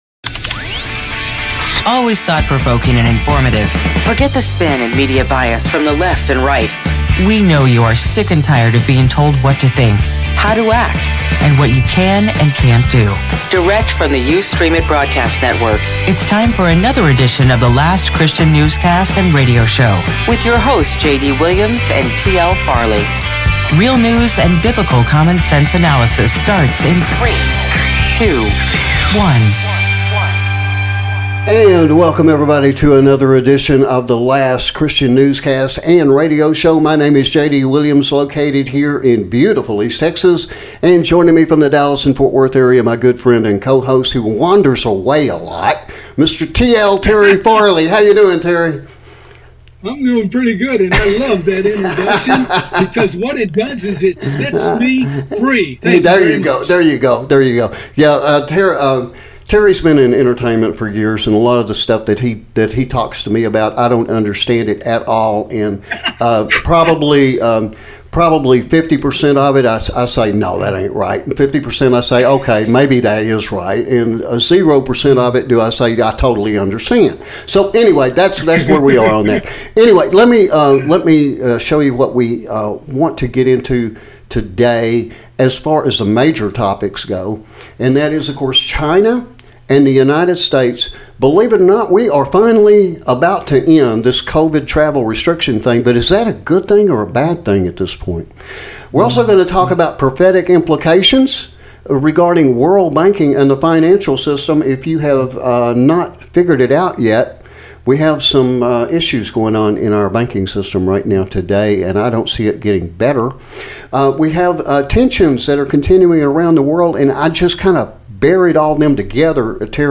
LCRS Newscast & Radio Show 14 March 2023